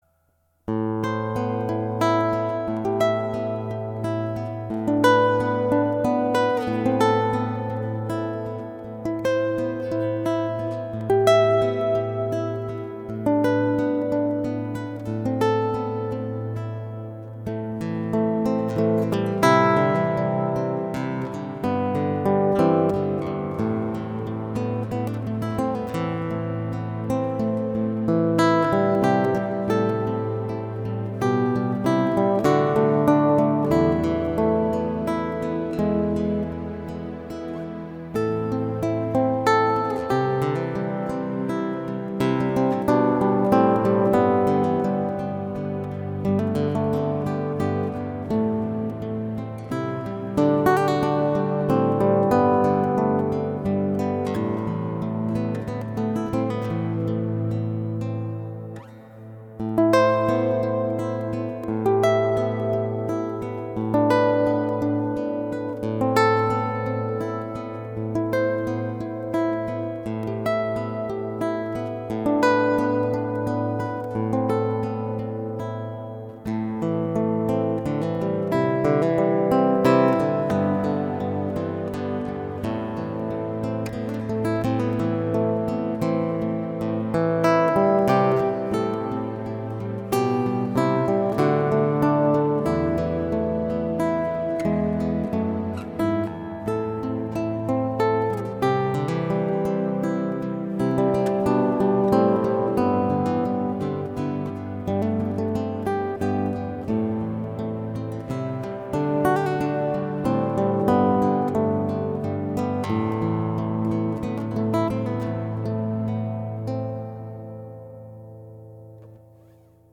Live Achtergrond Muziek
Wij verzorgen voor u als duo, instrumentale achtergrondmuziek voor uw intiem familie (tuin)feestje, dinner, borrel & recepties, musea, exposities.
keyboards/piano
Godin gitaar/ electrisch gitaar
Stijl van easy listening, jazz tot half klassiek en dit alles voor een redelijke prijs, ook voor events met een laag budget.